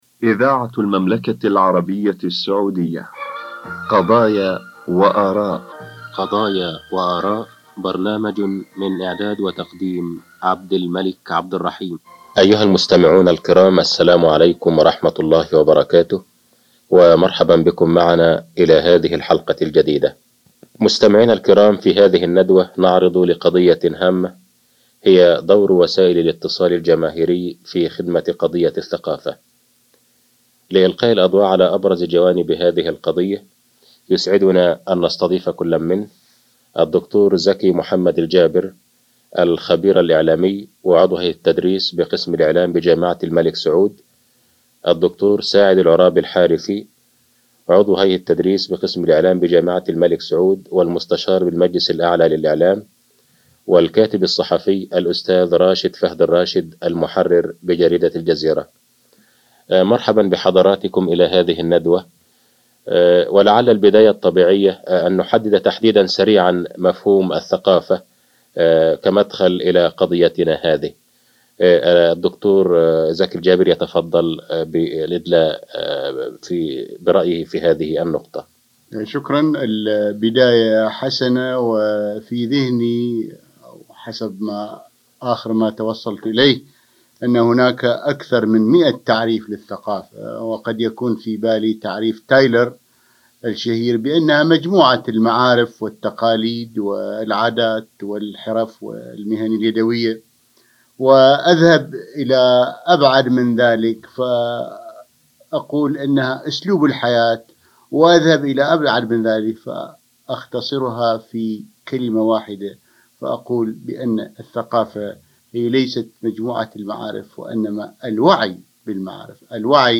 برنامج قضايا واراء- ندوة عن الصحافة 1986م